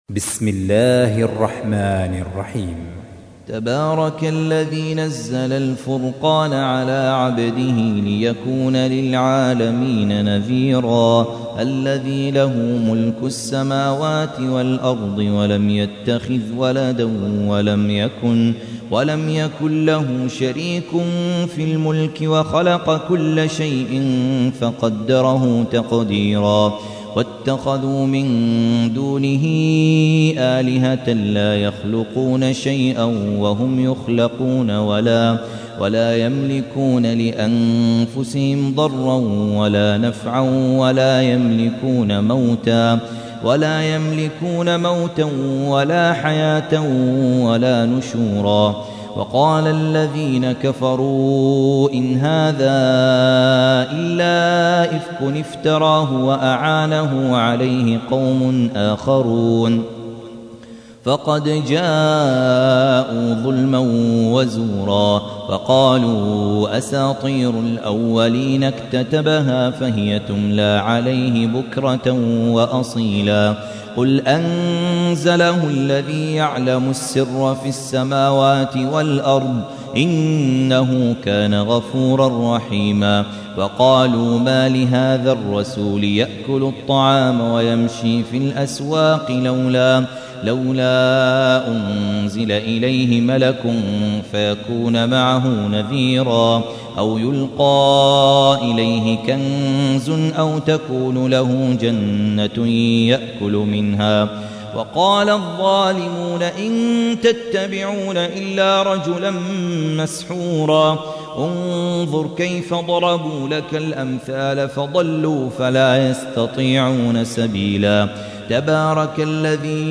تحميل : 25. سورة الفرقان / القارئ خالد عبد الكافي / القرآن الكريم / موقع يا حسين